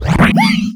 alianhit1.wav